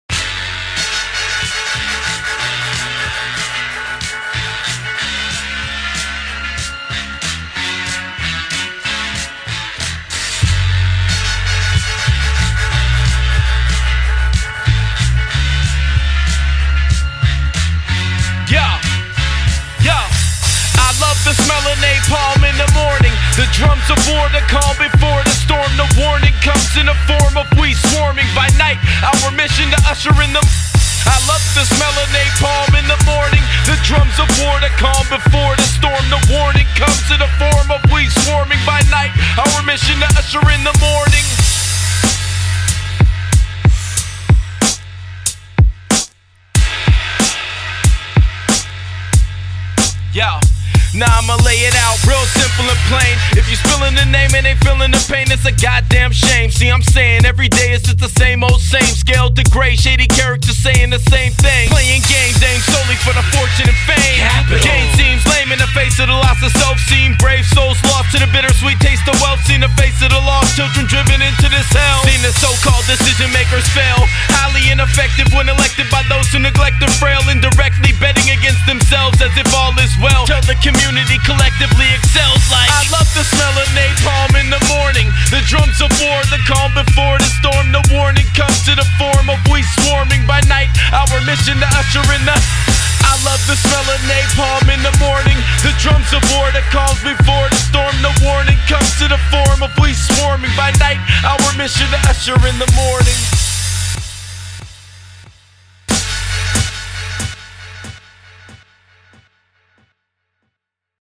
Recorded at Ground Zero Studios & Seattle ChopShop